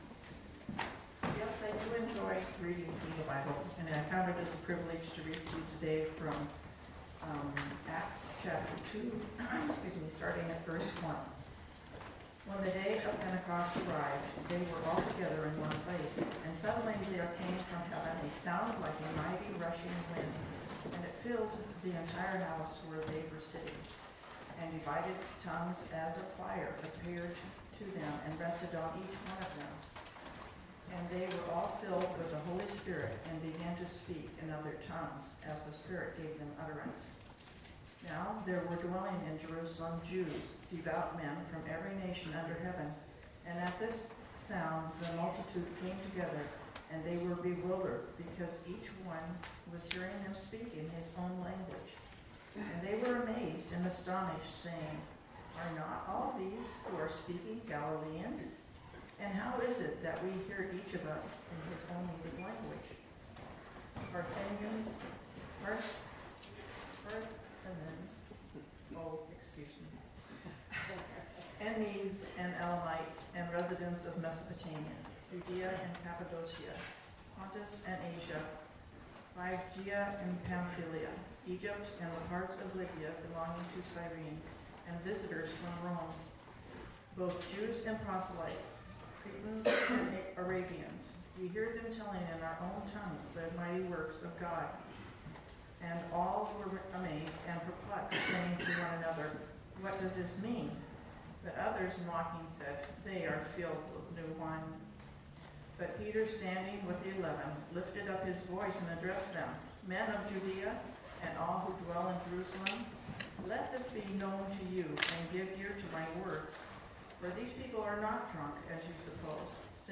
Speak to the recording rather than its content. Acts 2:1-21 Service Type: Sunday Morning Bible Text